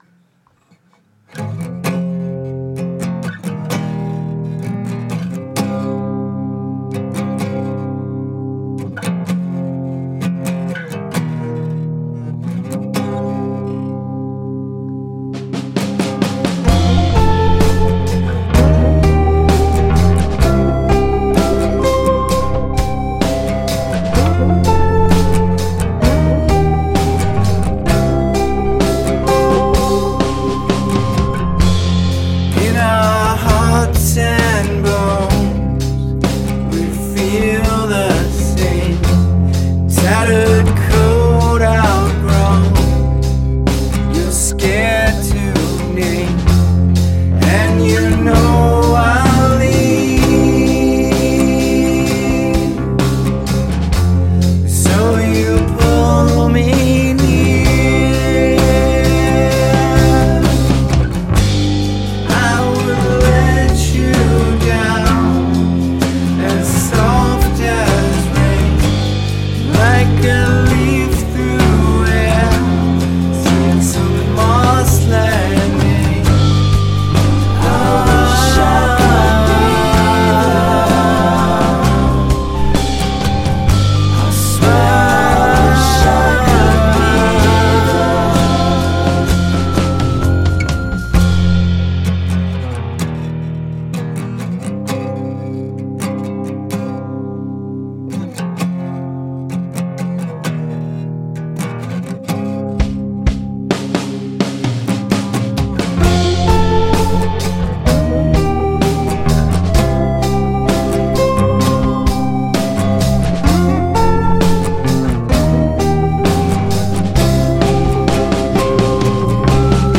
It's an interesting arrangement.